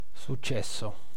Ääntäminen
Synonyymit terme résultat Ääntäminen France (nord): IPA: /a.bu.tis.mɑ̃/ Haettu sana löytyi näillä lähdekielillä: ranska Käännös Ääninäyte Substantiivit 1. risultato {m} IT 2. successo {m} IT 3. esito {m} Suku: m .